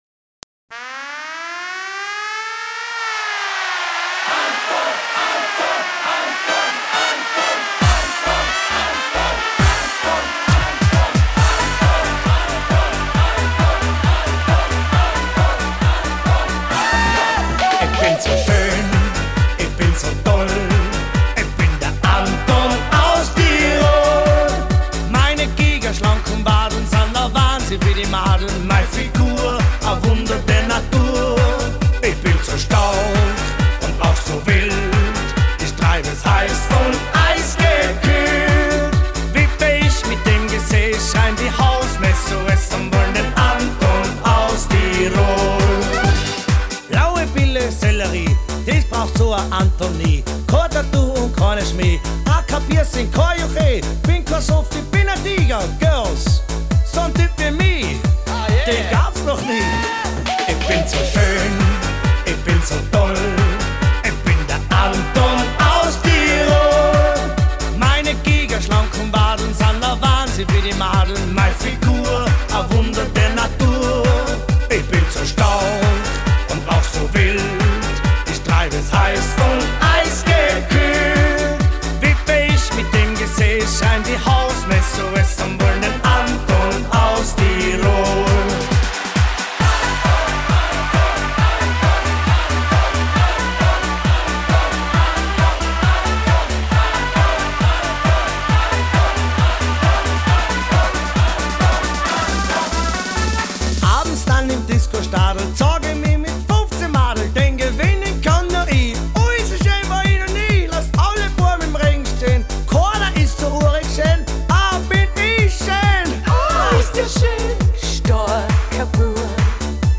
Schlager ALT